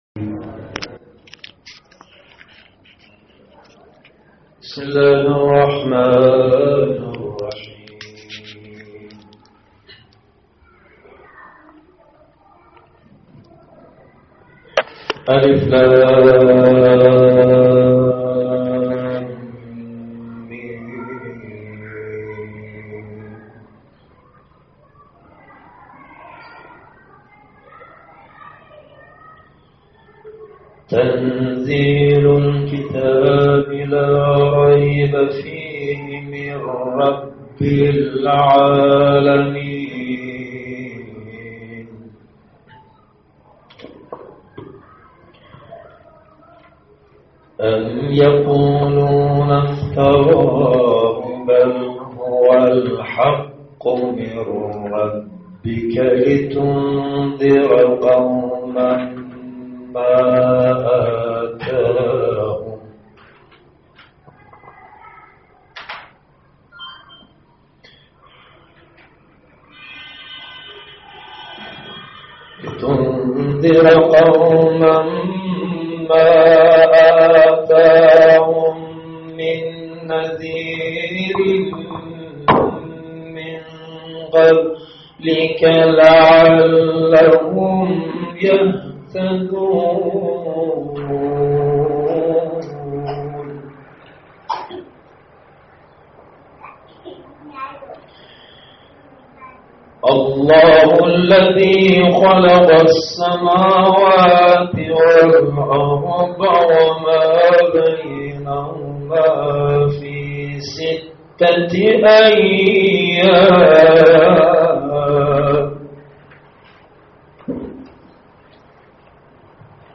جلسه عمومی مادران سخنرانی